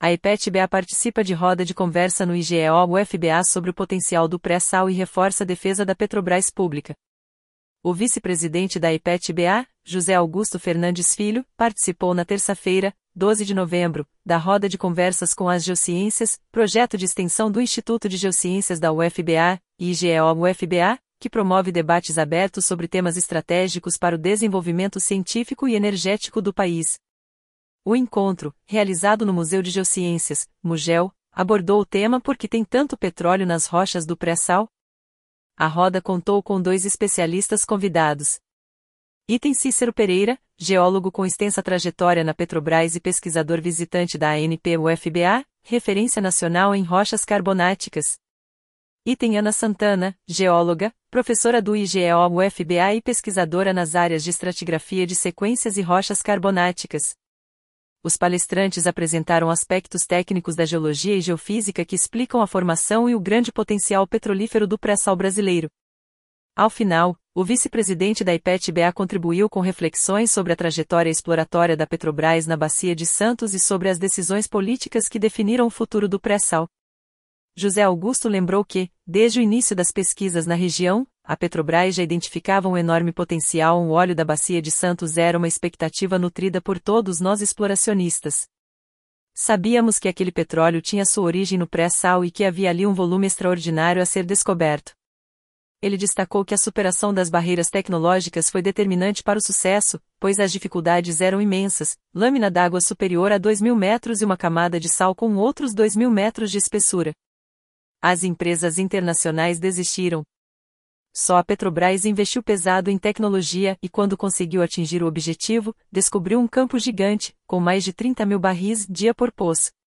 O encontro, realizado no Museu de Geociências (MUGEO), abordou o tema “Por que tem tanto petróleo nas rochas do Pré-Sal?”.